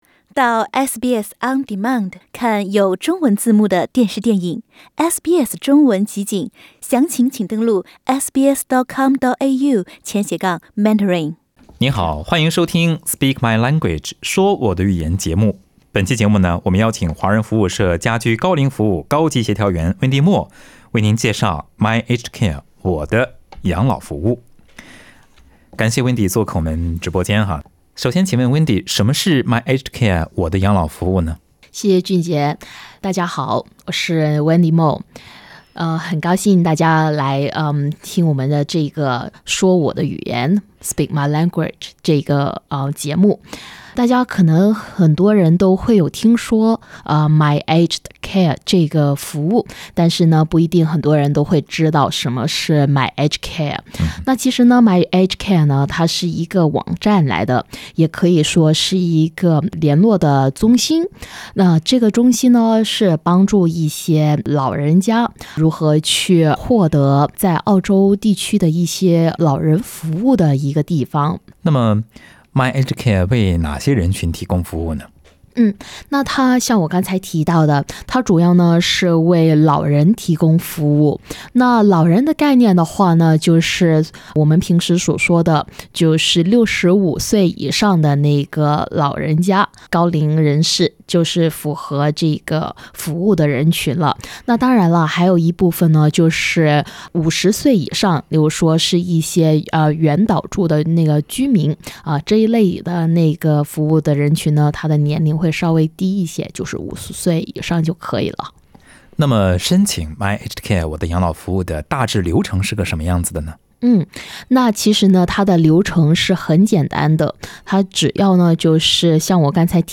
Speak My Language: Conversations about ageing well Source: Ethnic Communities Council NSW